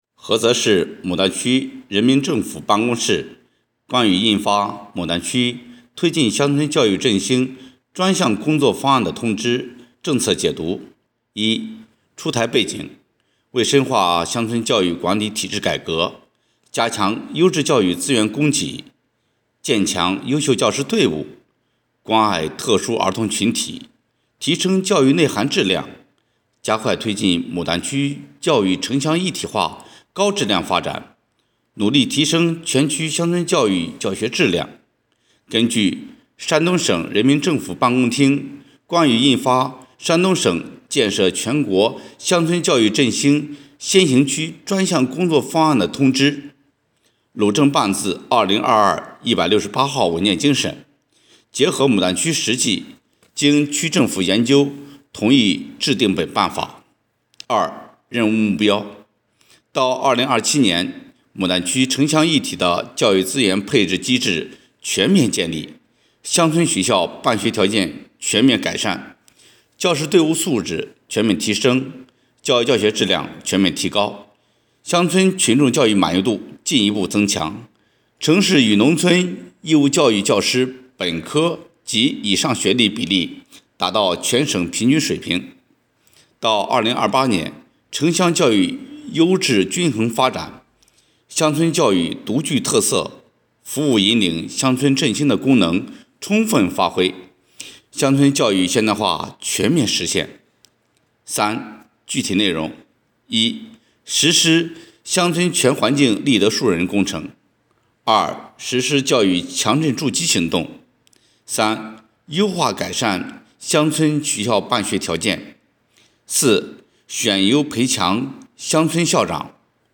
【音频解读】菏区政办字〔2023〕16号 菏泽市牡丹区人民政府办公室关于印发牡丹区推进乡村教育振兴专项工作方案的通知
乡村教育振兴专项工作方案政策解读.mp3